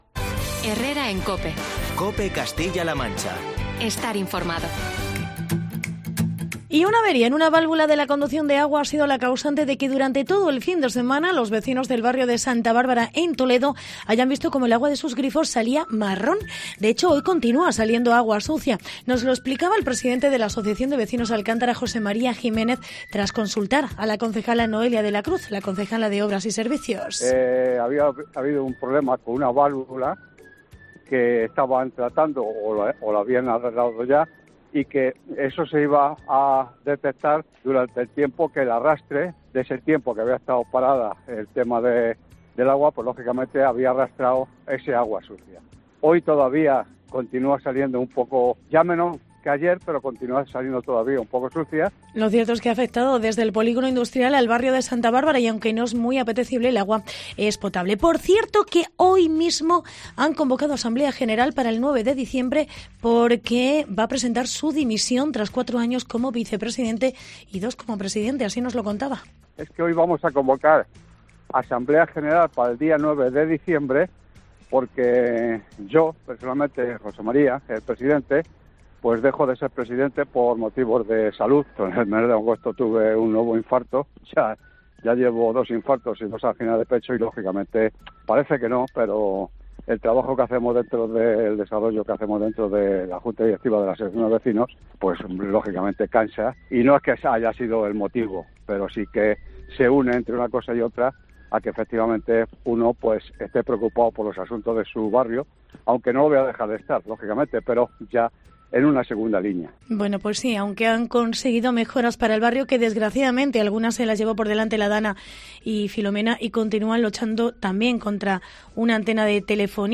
Reportaje AAVV Alcántara